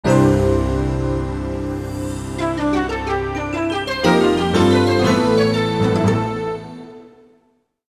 applied fade-out to last two seconds
Fair use music sample